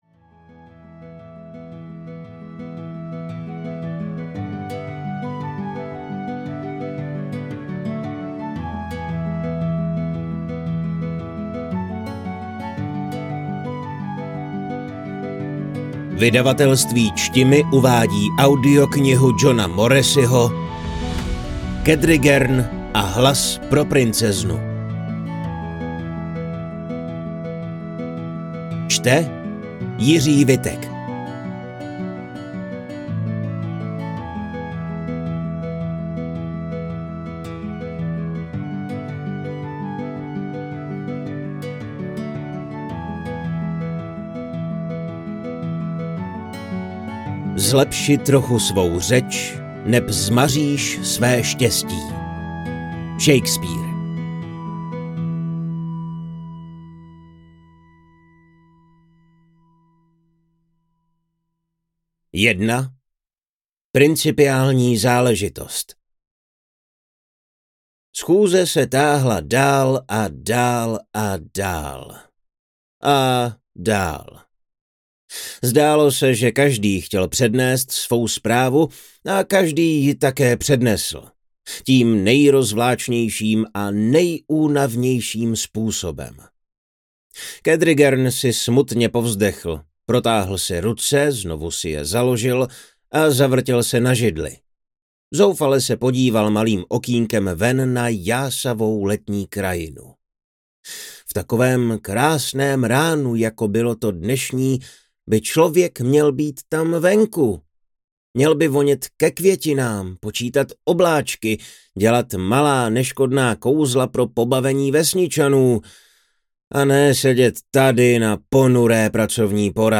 Kedrigern a hlas pro Princeznu audiokniha
Ukázka z knihy
kedrigern-a-hlas-pro-princeznu-audiokniha